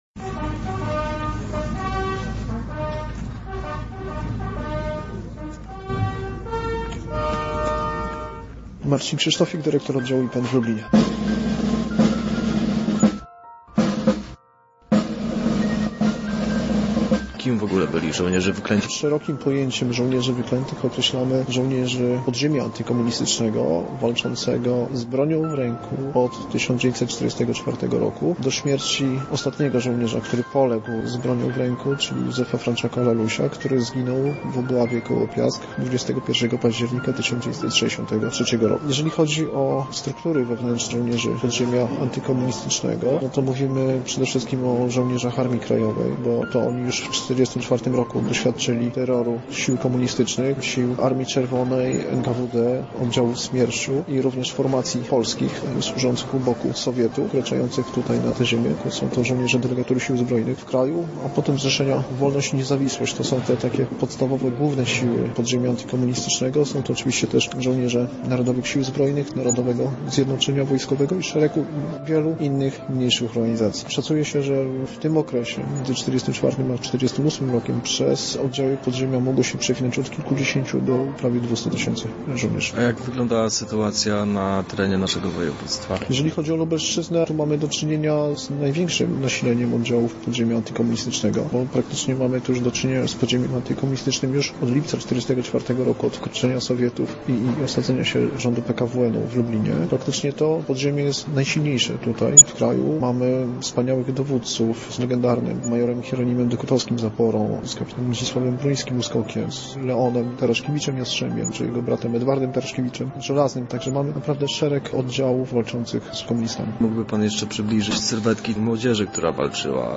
Na miejscu był nasz reporter